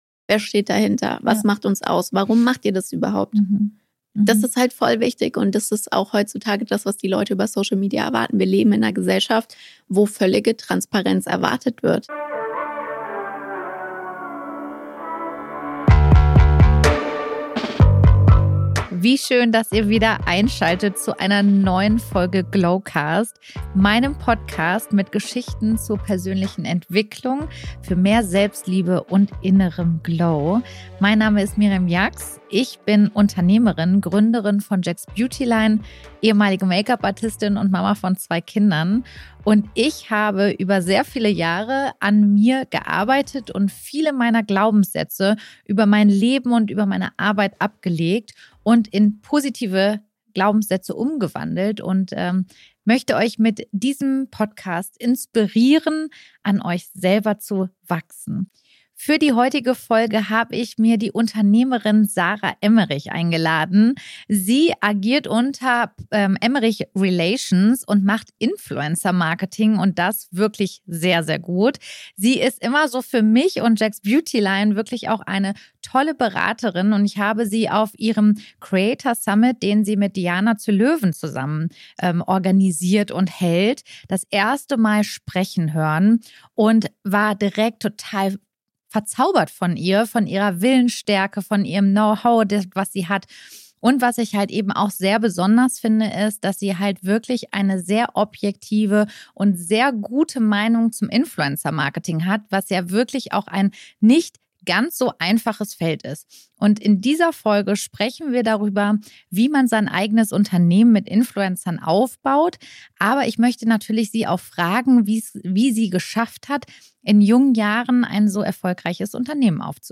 Ein tiefgründiges Gespräch, das jeden begeistern wird, der sich für die Kraft des Influencer Marketings interessiert.